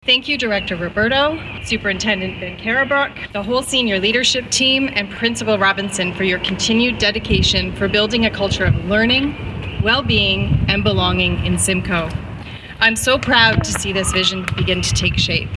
Groundbreaking ceremony held at West Elgin Public School
Chair of the Grand Erie District School Board Susan Gibson was on hand, thankful for all those with the vision to see this project come to life.